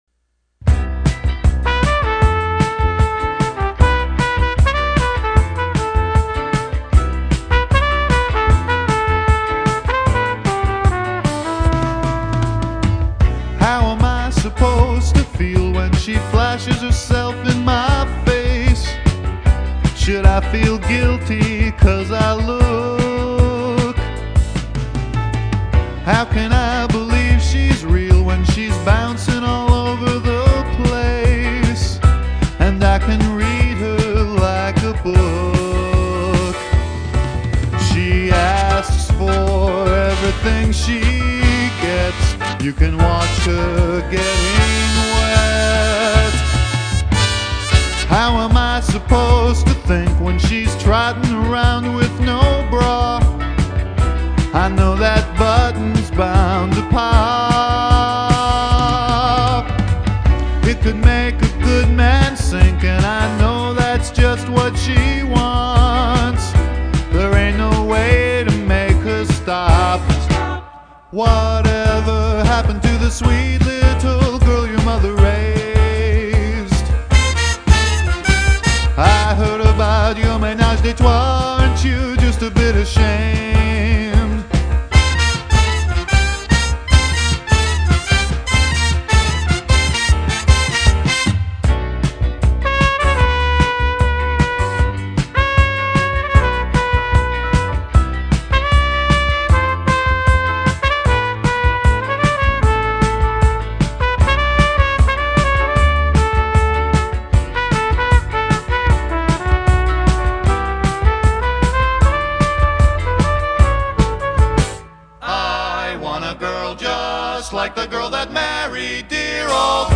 trumpet solo
bg vox
unknown bass and drums